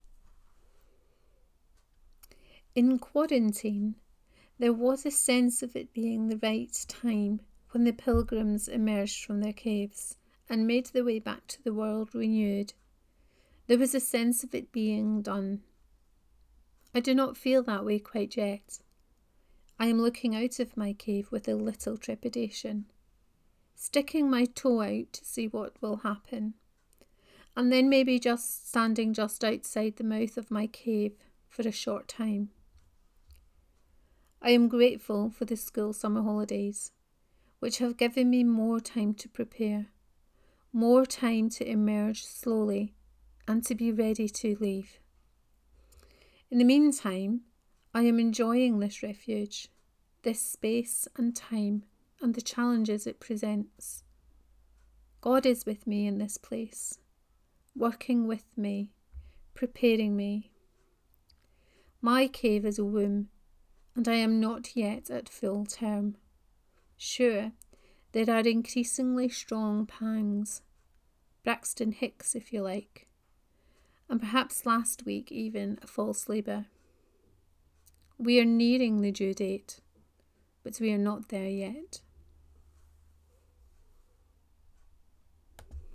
On being in the Cave 5: Reading of this post.